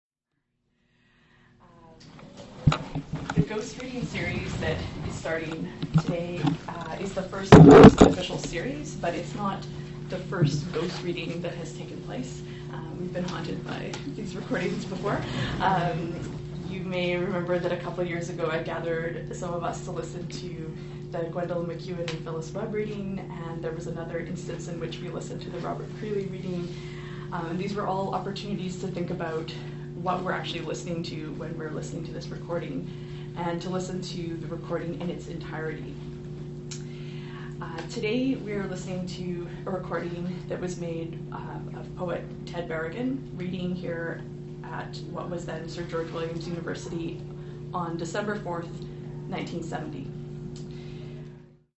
Transcription of the above audio recorded, from the first Ghost Reading (or was it the first?)